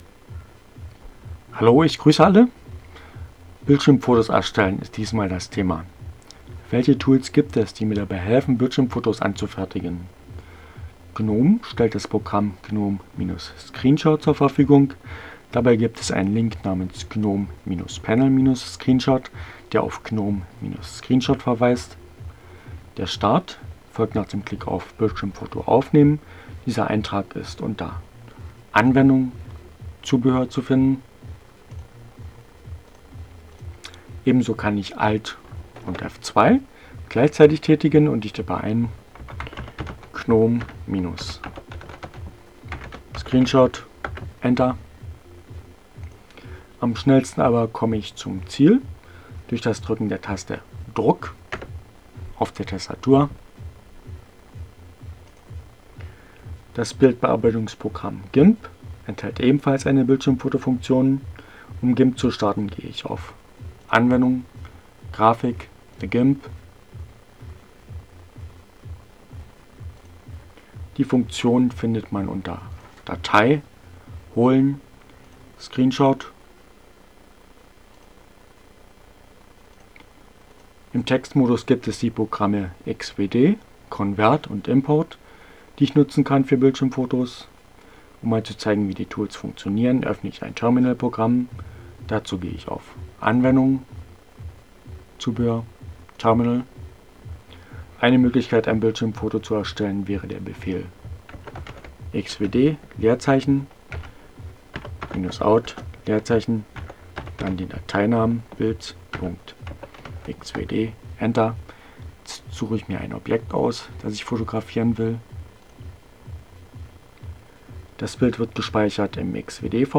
Vielen Dank. Selten so einen fundierten Screencast gesehen: Genau was ich gesuchte mit angenehmer stimme ohne diese "Schmatzer".Jetzt bin ich informiert und kann mein Screenshot-Problem endlich lösenn ;-)